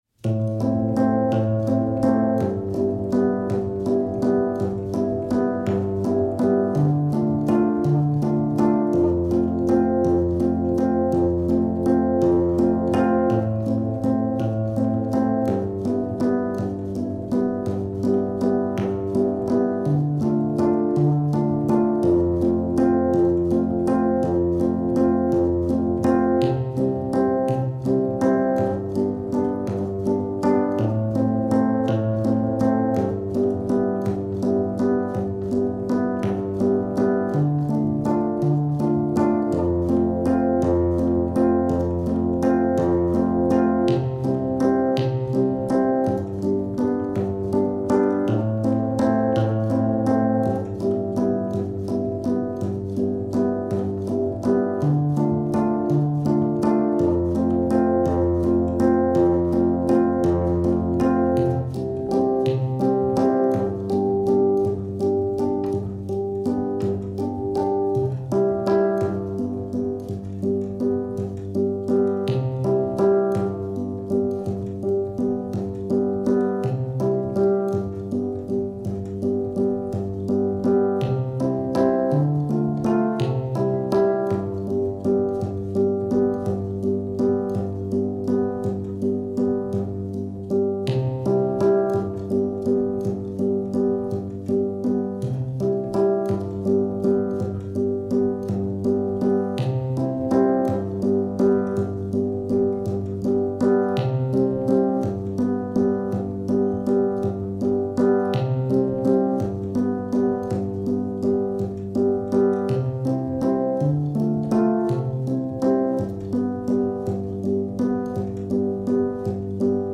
This is the music only [no lyrics] version of the song.